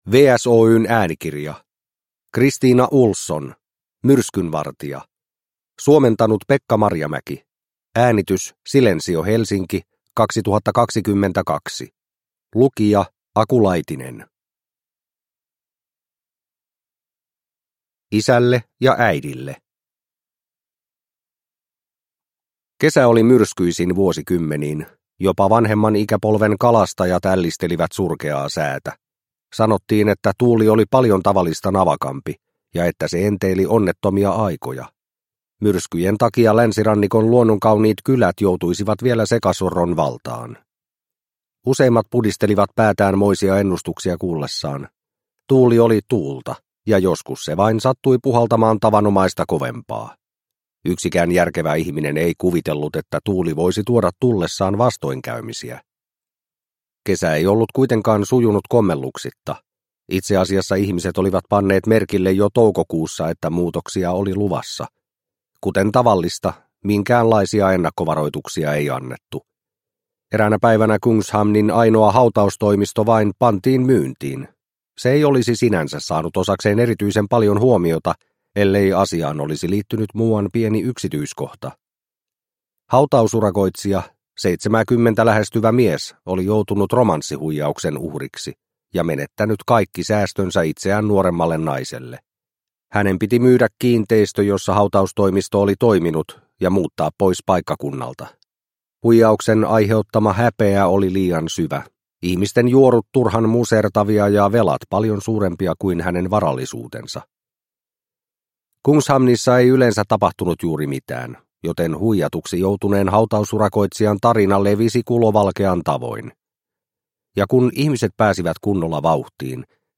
Myrskynvartija – Ljudbok – Laddas ner